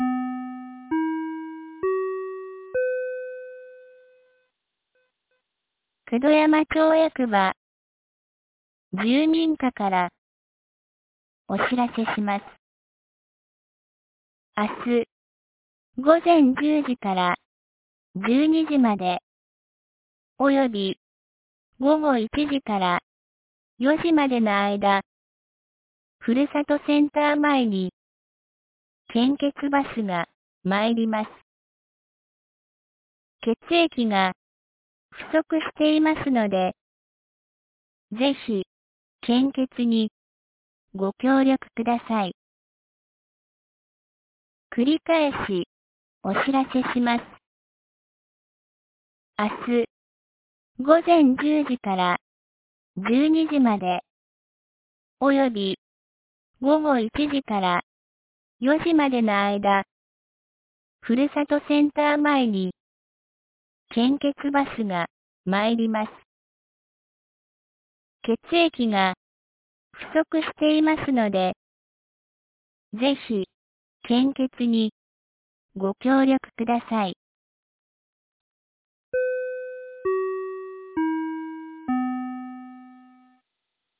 2024年06月10日 10時00分に、九度山町より全地区へ放送がありました。
放送音声